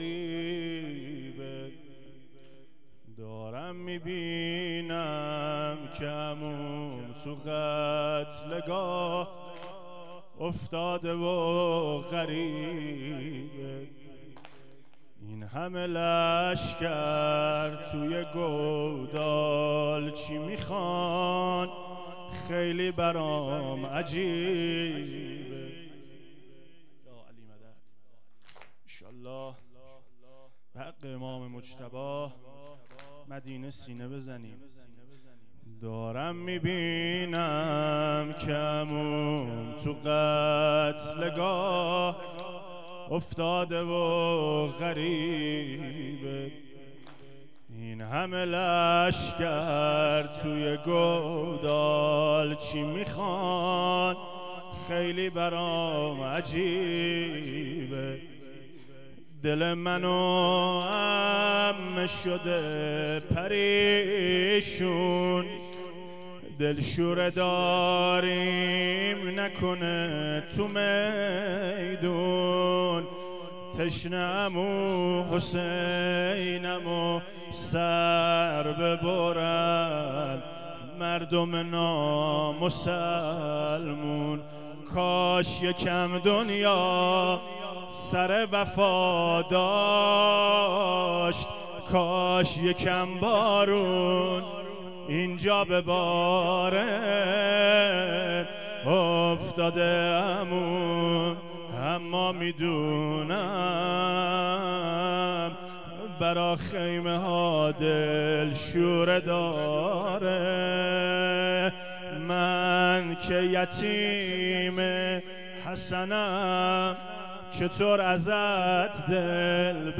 محرم 99 شب پنجم